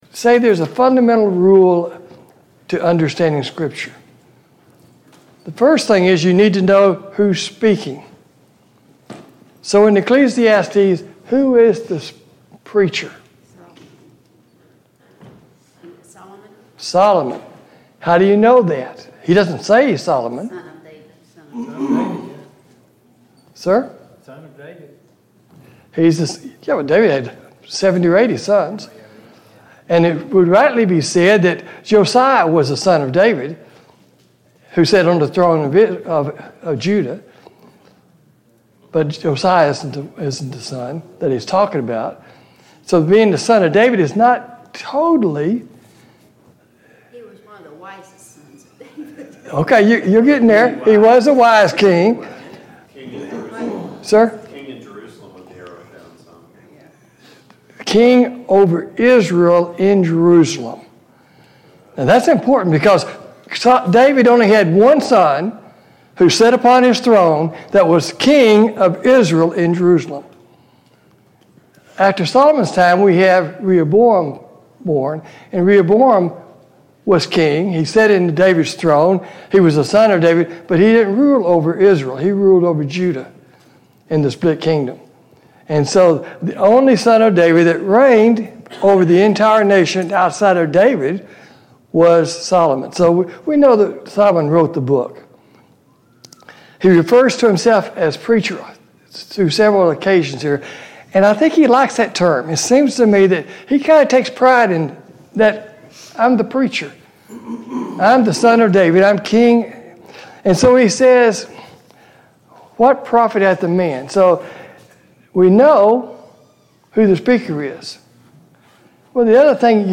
Ecclesiastes 1 Service Type: Sunday Morning Bible Class « 34.